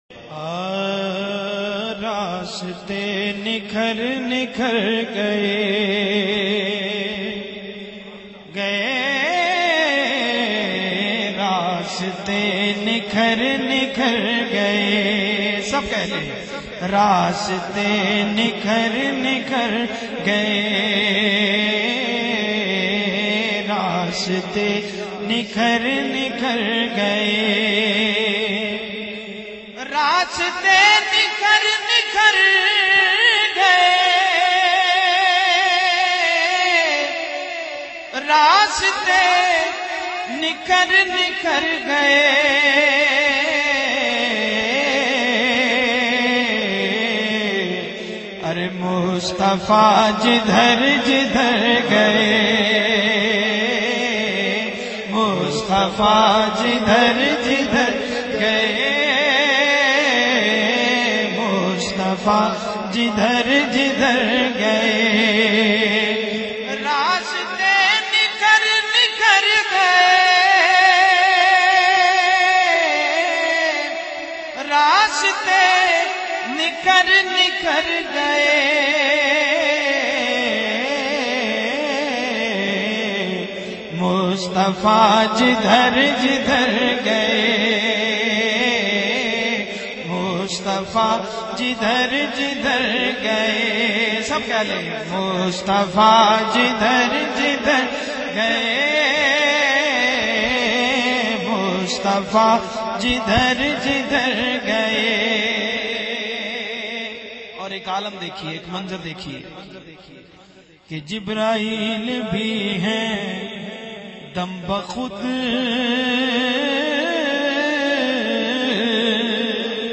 naat